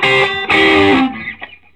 Index of /90_sSampleCDs/USB Soundscan vol.22 - Vintage Blues Guitar [AKAI] 1CD/Partition C/04-SOLO D125